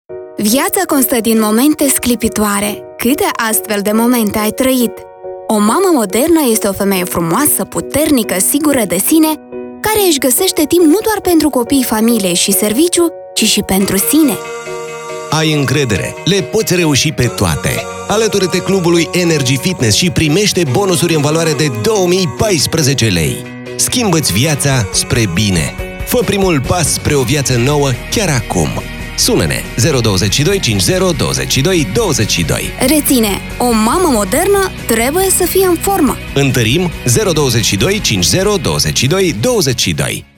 Moldovyaca Seslendirme
Kadın Ses